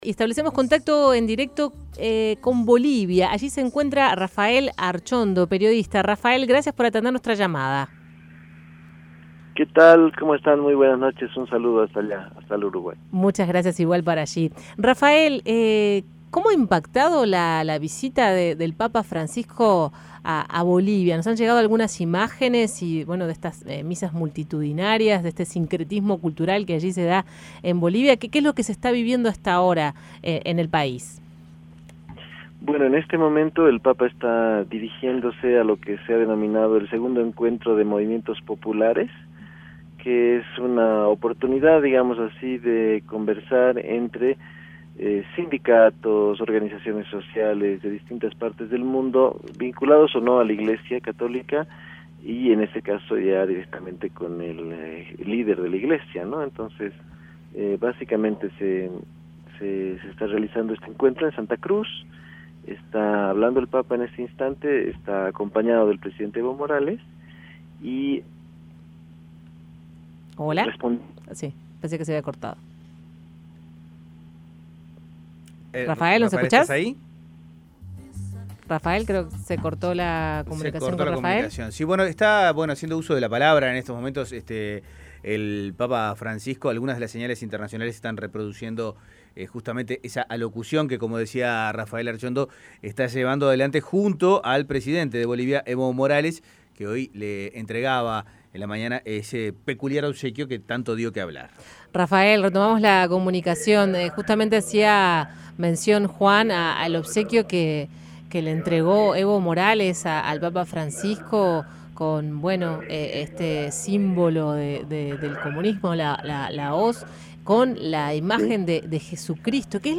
Escuche al periodista venezolano